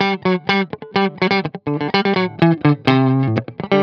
09 GuitarFunky Loop A.wav